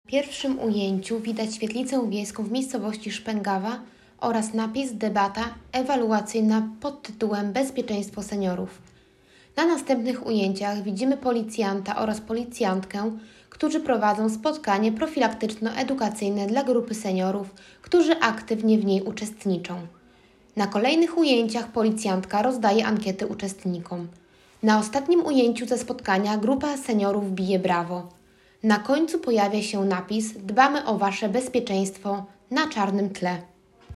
Debata społeczna pt. „Bezpieczeństwo seniorów”
Spotkanie odbyło się w bardzo przyjaznej atmosferze, w której udział wzięło 30 osób. Seniorzy chętnie zadawali pytania i włączali się do dyskusji.